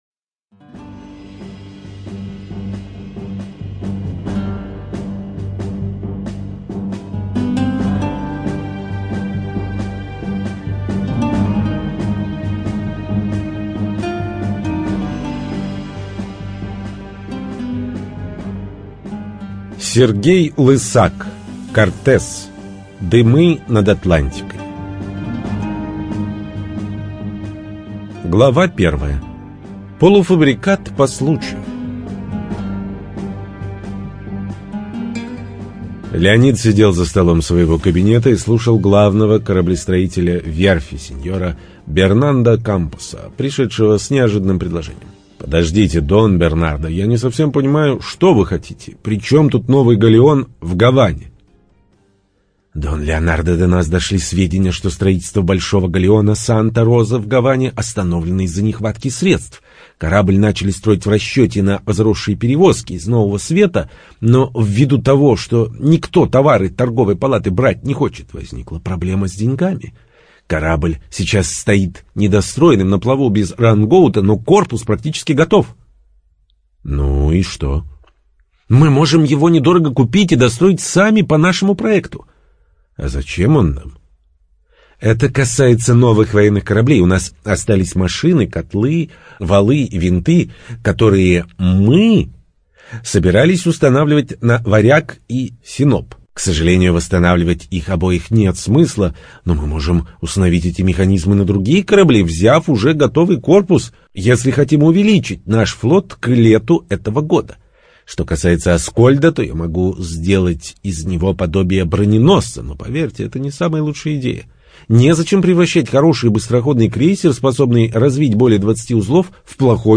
ЖанрФантастика, Боевики